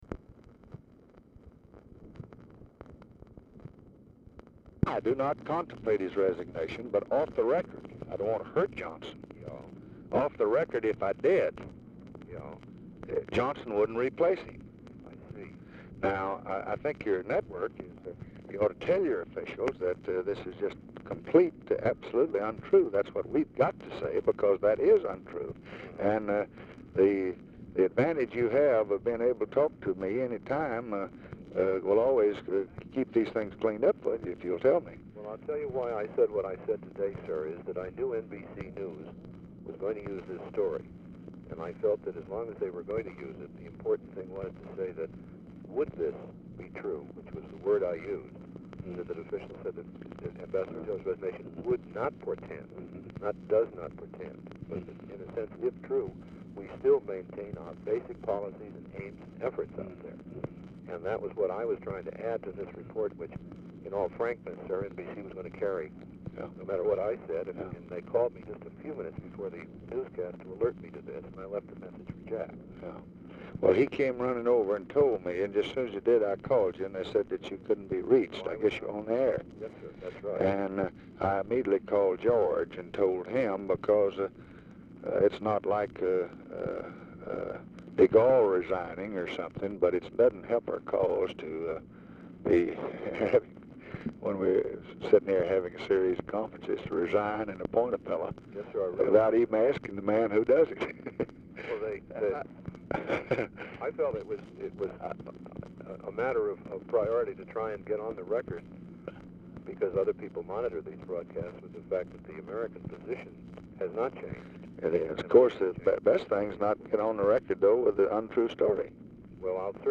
Telephone conversation # 7191, sound recording, LBJ and JOHN CHANCELLOR, 3/31/1965, 11:16AM | Discover LBJ
Format Dictation belt
Location Of Speaker 1 Mansion, White House, Washington, DC